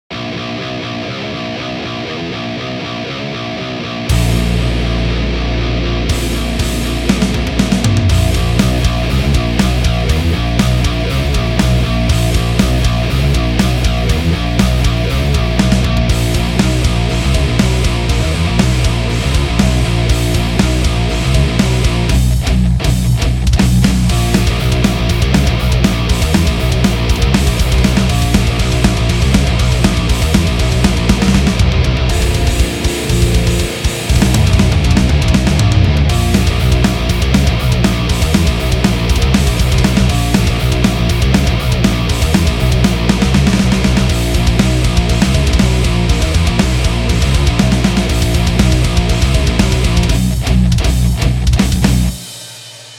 grove metal (��������) - Metal Foundry/trilian/Amplitube/EC-1000